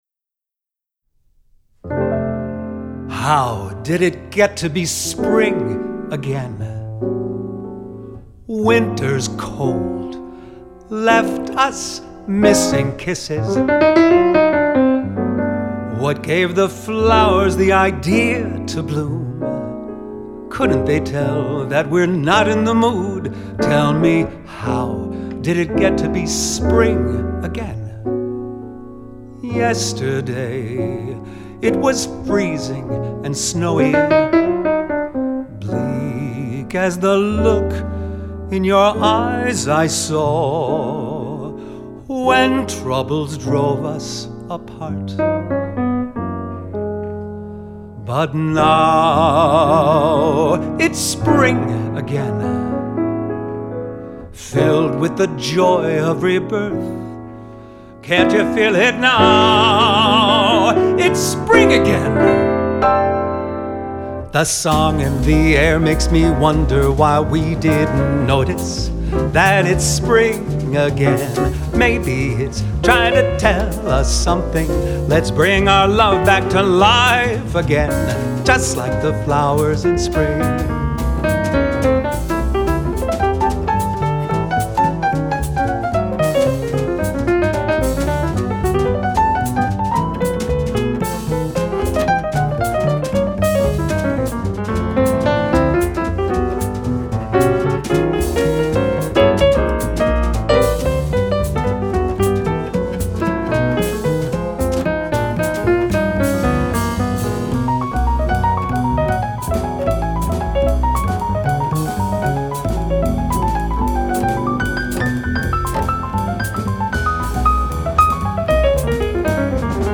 File: Jazz+Vox